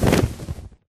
Minecraft / mob / enderdragon / wings2.ogg
wings2.ogg